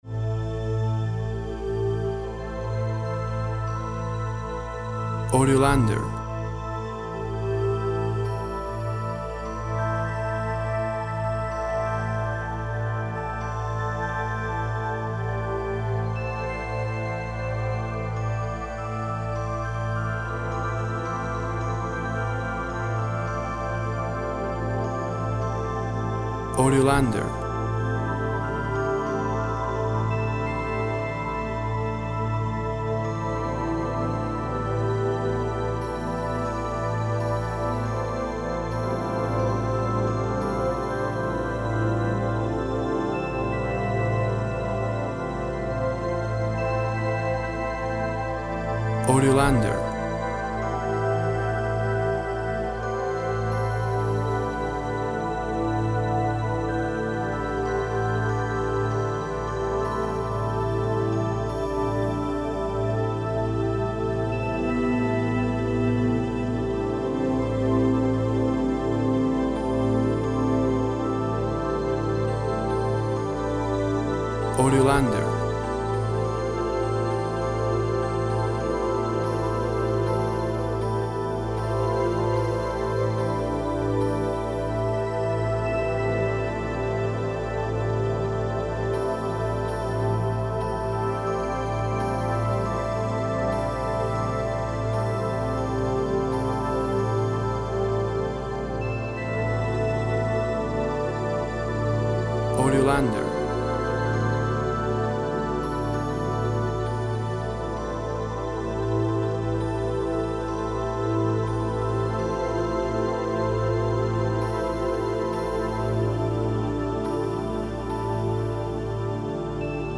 Soft persistent and hypnotic synth sounds.
Tempo (BPM) 48